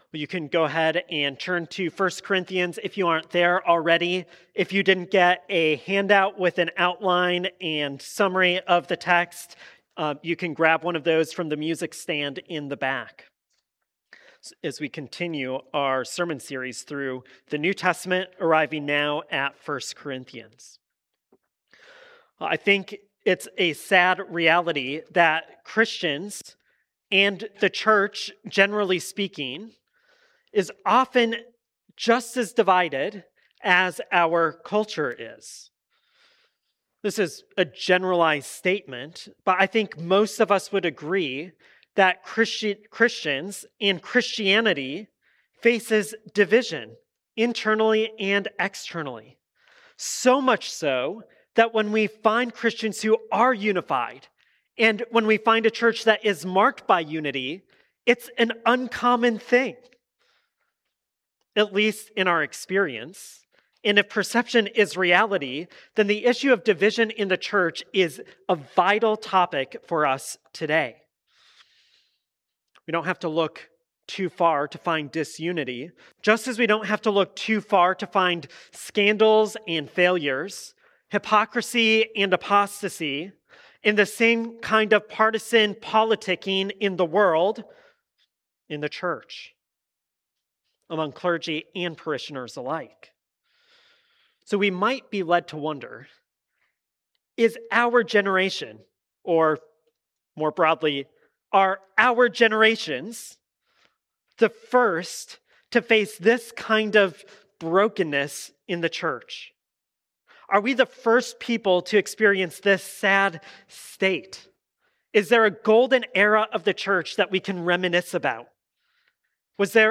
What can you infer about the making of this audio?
Sermons preached at Resurrection Church (Burnsville, MN)